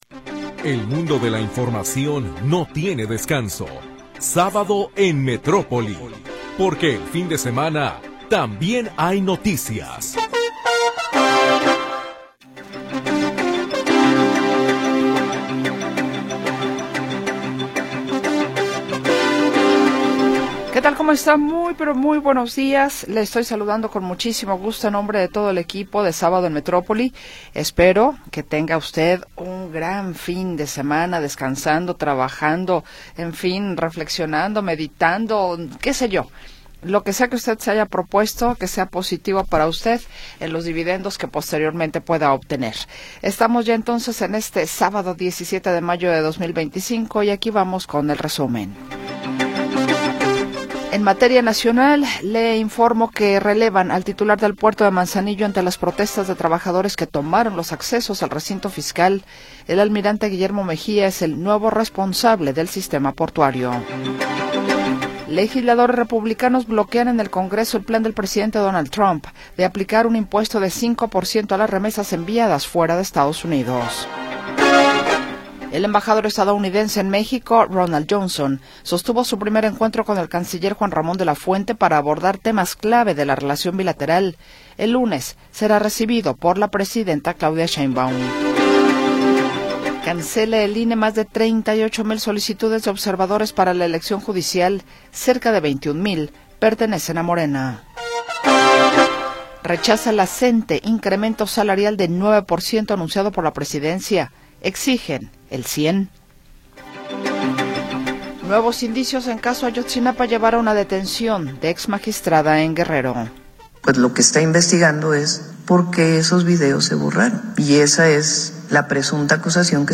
Noticias y entrevistas sobre sucesos del momento
Primera hora del programa transmitido el 17 de Mayo de 2025.